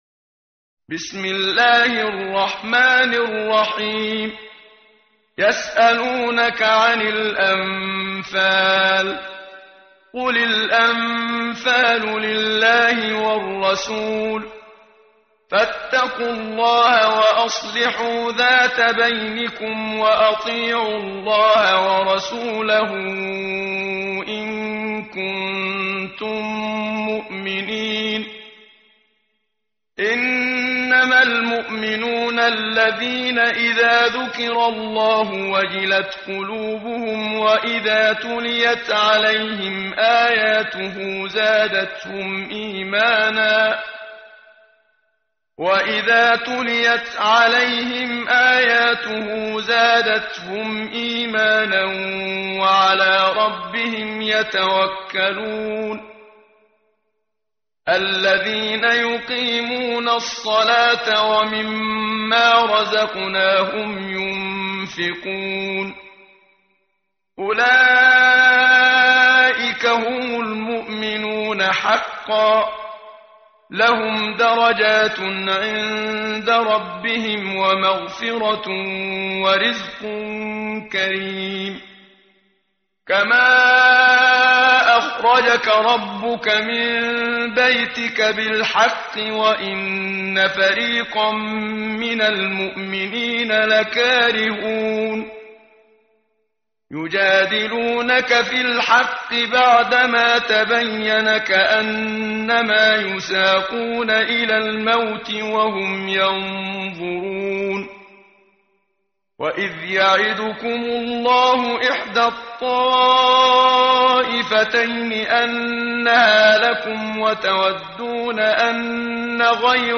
ختمة مرتلة صفحة صفحة /ص177